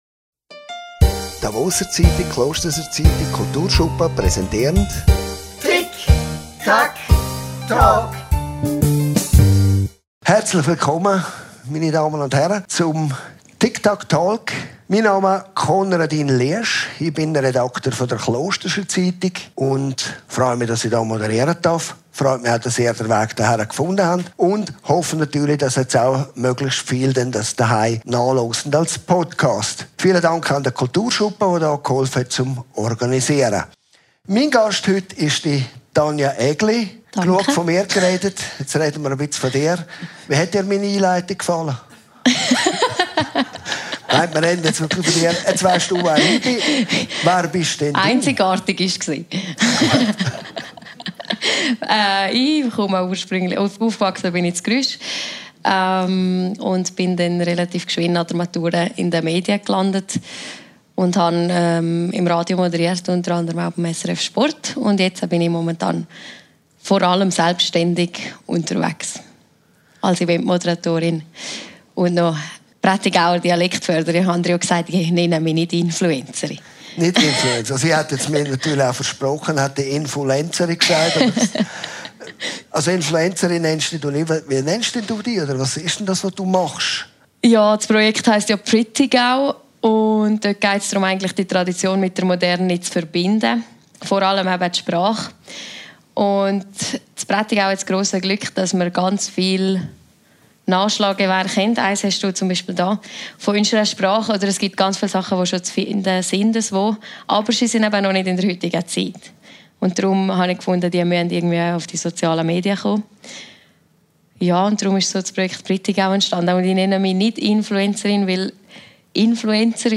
Talkshow und Podcast, präsentiert von der «Davoser Zeitung», «Klosterser Zeitung», Kulturschuppen Klosters.